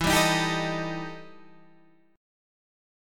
EmM7bb5 chord